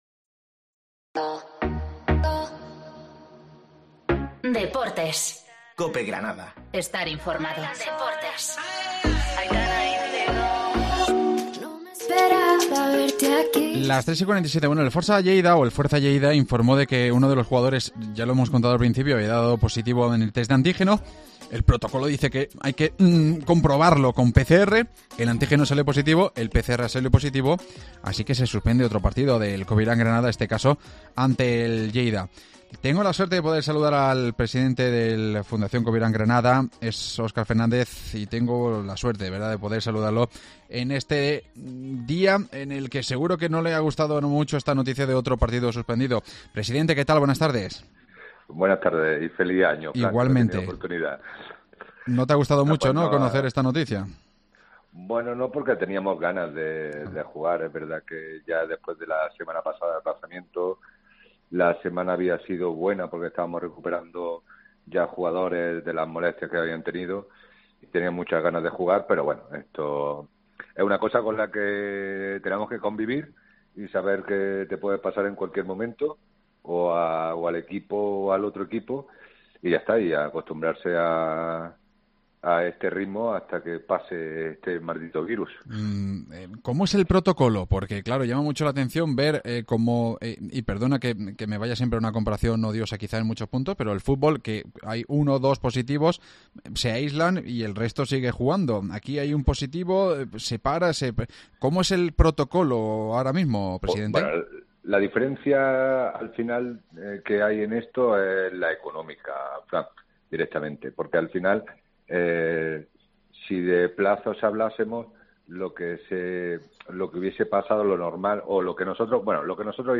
AUDIO: Ha estado en Deportes COPE Granada entrevistado